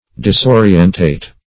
Disorientate \Dis*o"ri*en*tate\, v. t.